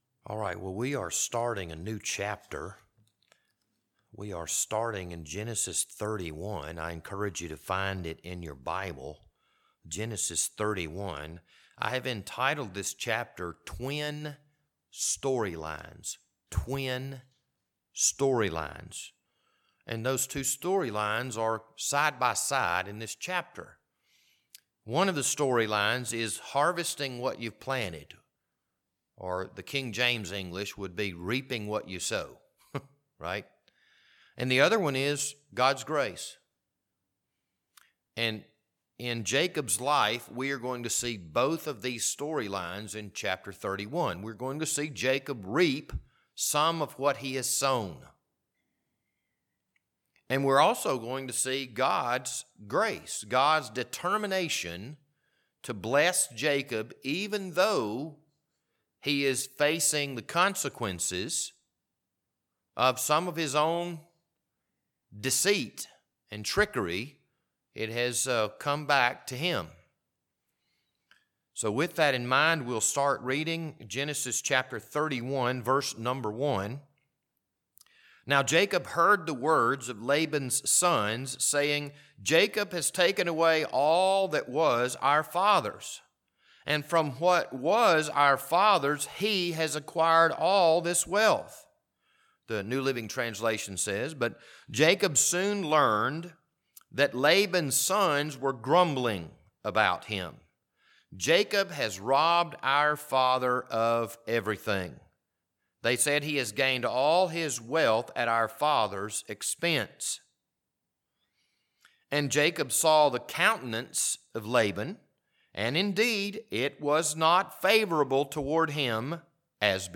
This Wednesday evening Bible study was recorded on September 13th, 2023.